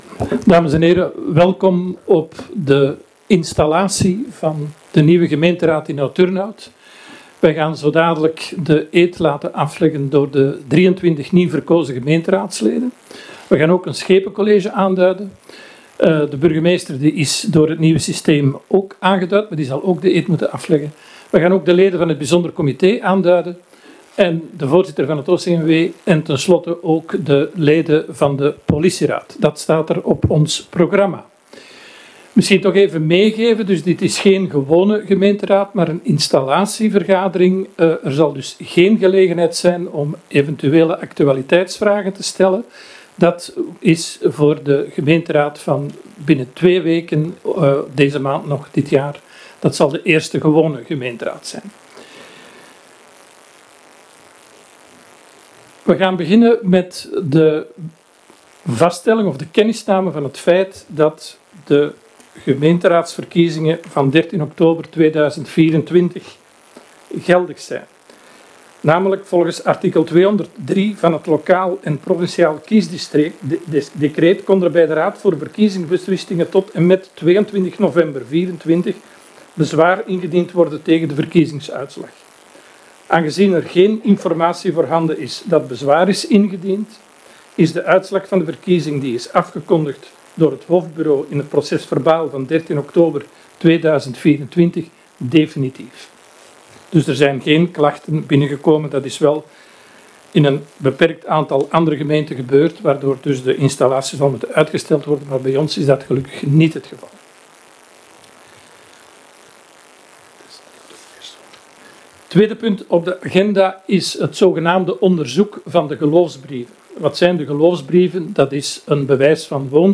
Installatievergadering
Gemeentehuis